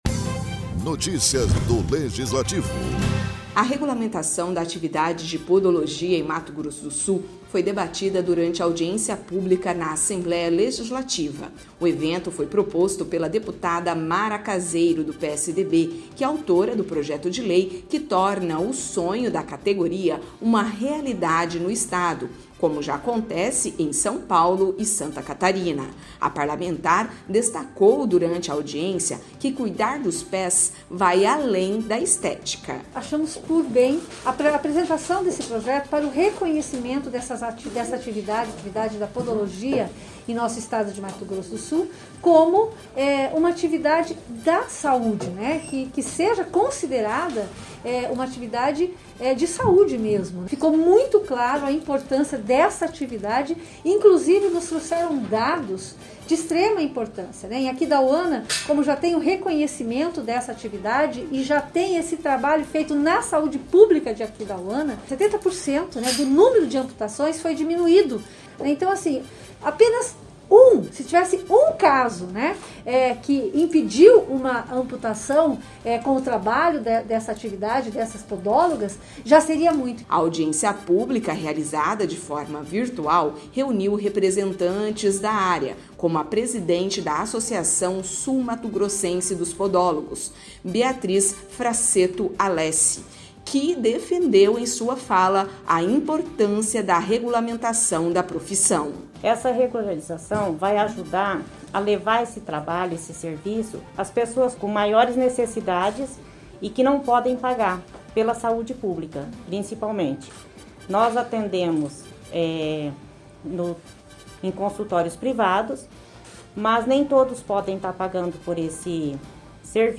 A Regulamentação da atividade de podologia em Mato Grosso do Sul foi debatida durante audiência pública na Assembleia Legislativa.